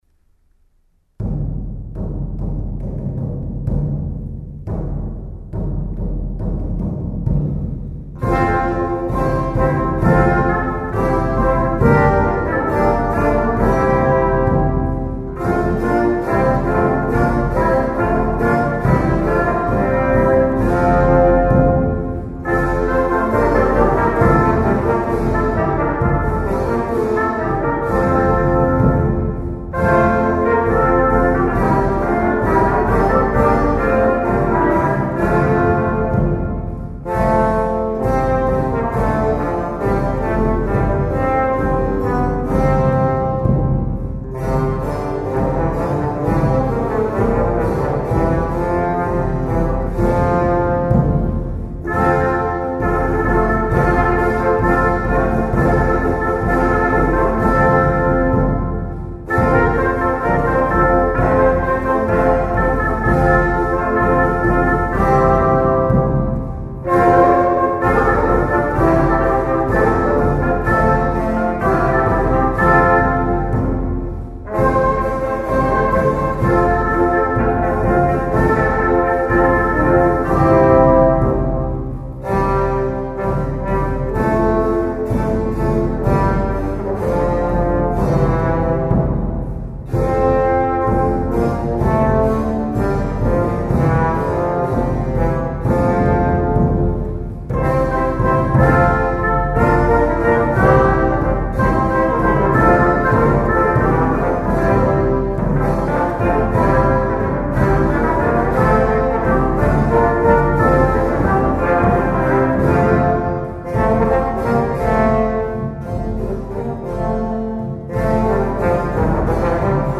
la registrazione è stata effettuata nel Maggio del 2011 nell'Oratorio dell’Annunziata di Piana Crixia (Savona).
Sono state utilizzate esclusivamente copie di strumenti rinascimentali.
L’eco è solo quella naturale dell'oratorio,